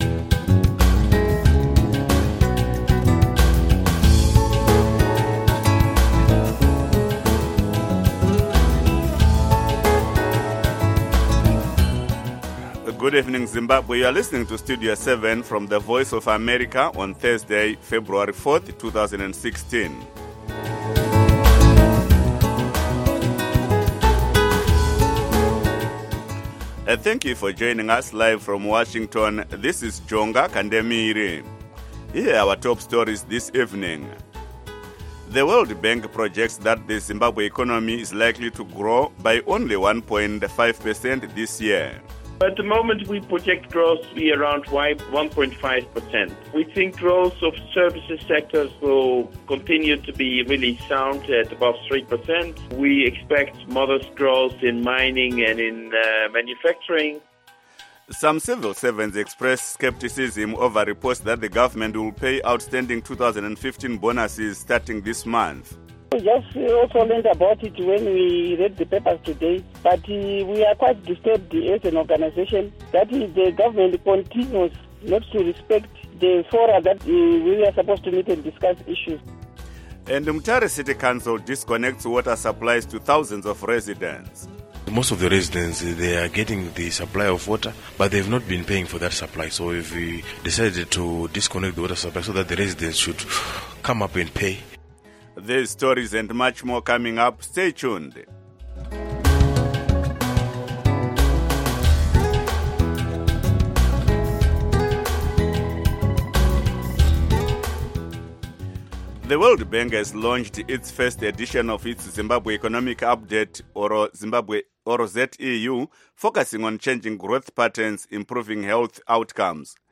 Studio 7 has been providing Zimbabwe with objective, reliable and balanced radio news broadcasts since 2003 and has become a highly valued alternative point of reference on the airwaves for many thousands of Zimbabweans. Studio 7 covers politics, civil society, the economy, health, sports, music, the arts and other aspects of life in Zimbabwe.